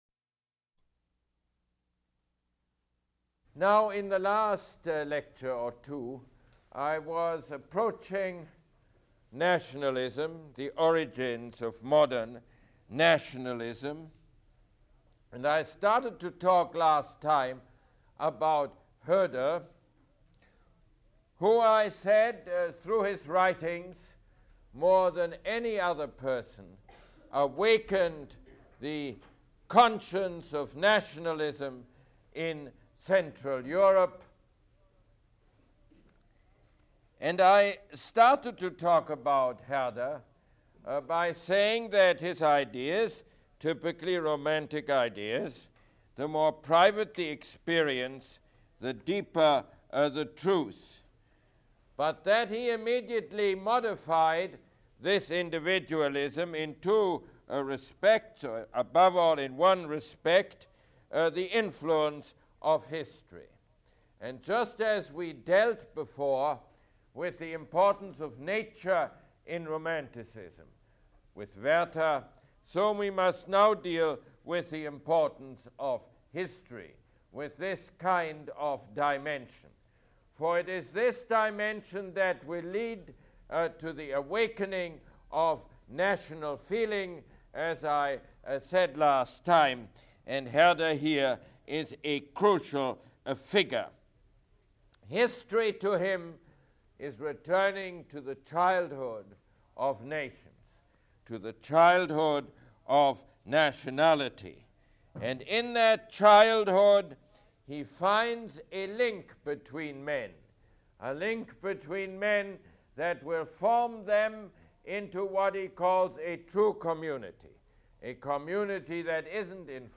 Mosse Lecture #16